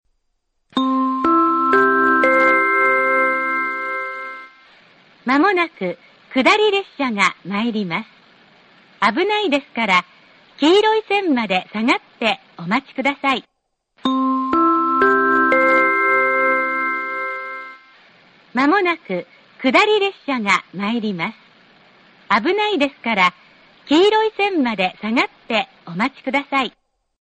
１番線接近放送 放送が言い終わる頃には列車はホームに入ってきています。